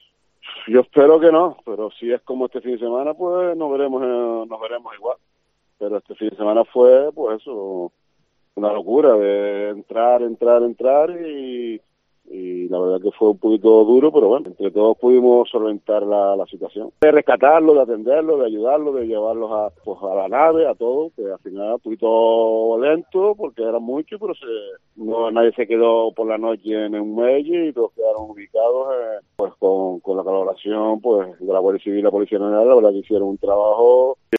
voluntario en Emerlan Lanzarote